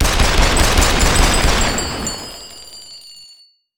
shells.wav